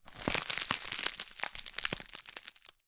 Звуки салата
Шум свежего салата, перемешиваемого в ладони